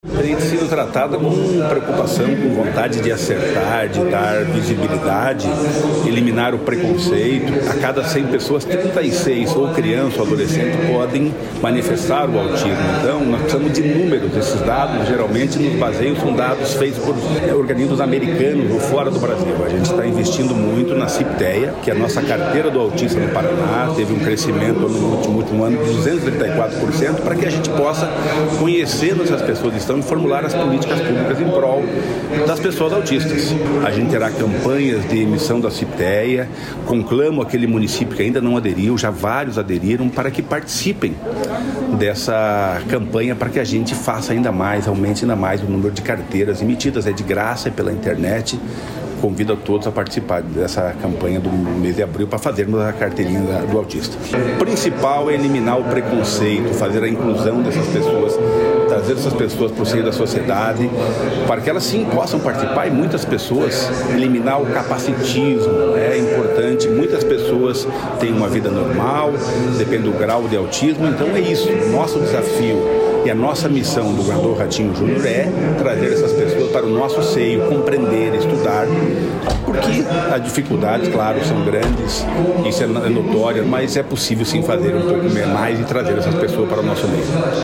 Sonora do secretário do Desenvolvimento Social e Família, Rogério Carboni, sobre o mutirão em abril para confecção da Carteirinha do Autista